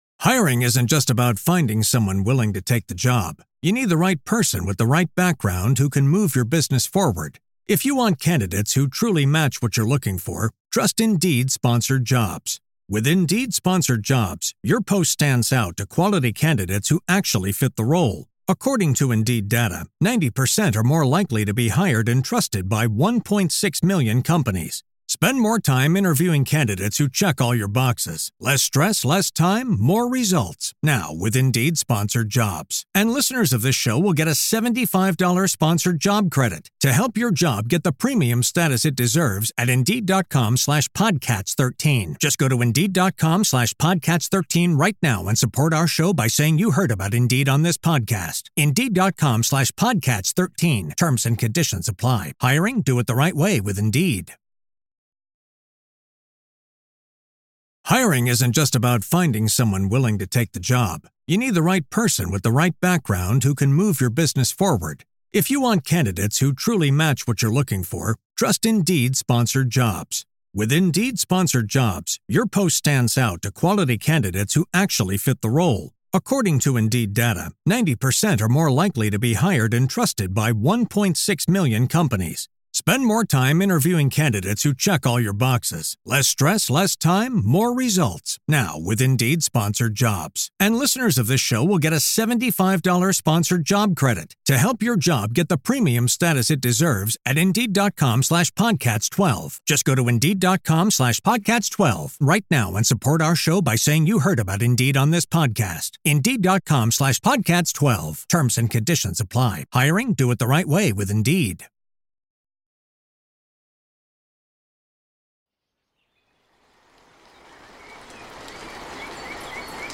Foggy Forest Morning with Distant Owl Calls for Meditation and Healing – Evening Wind Mix
Each episode of Send Me to Sleep features soothing soundscapes and calming melodies, expertly crafted to melt away the day's tension and invite a peaceful night's rest.